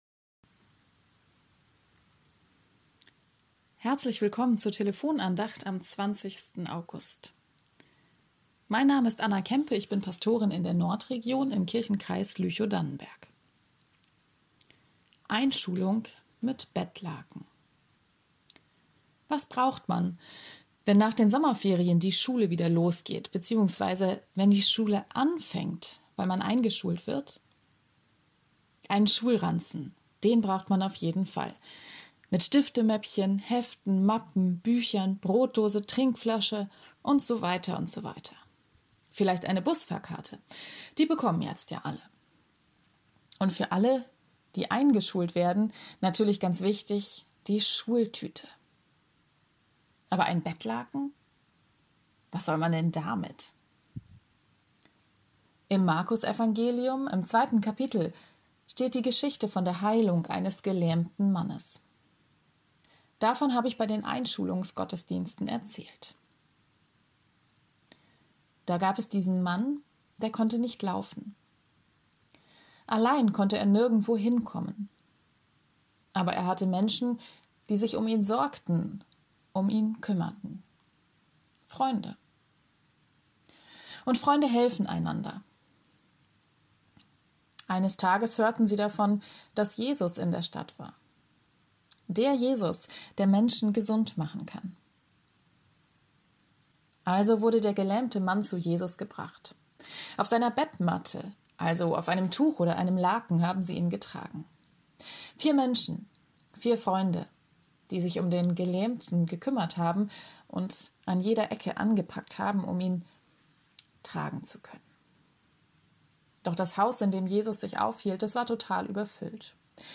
Einschulung mit Bettlaken ~ Telefon-Andachten des ev.-luth. Kirchenkreises Lüchow-Dannenberg Podcast